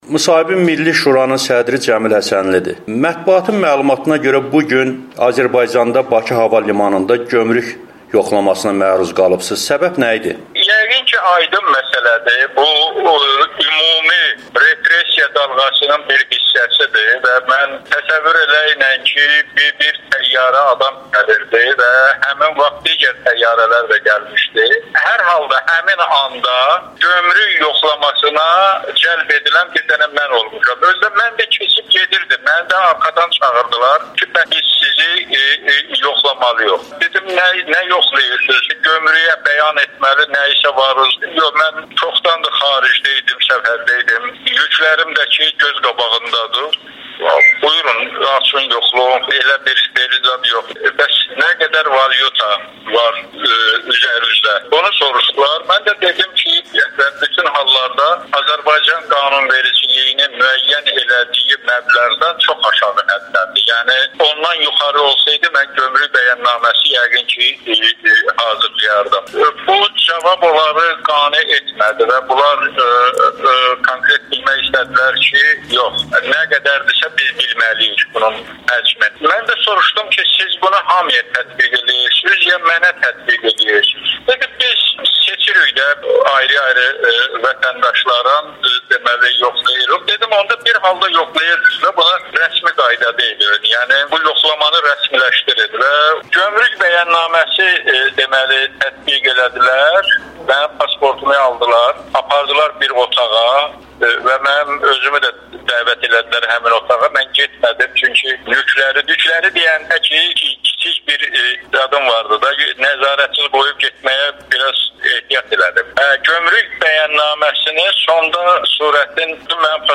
Milli Şuranın sədri Cəmil Həsənlinin Amerikanın Səsinə müsahibəsi